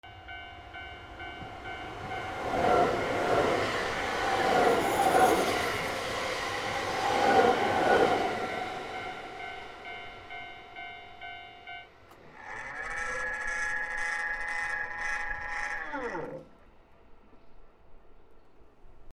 / E｜乗り物 / E-60 ｜電車・駅 / 3 踏み切りより
電車 踏切 遮断機